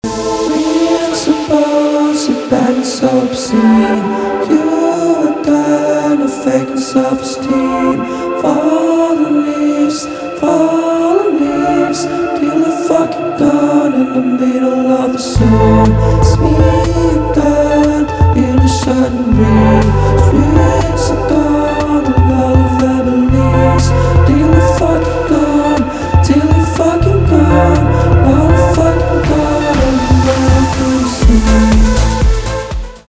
электроника
спокойные
басы , гитара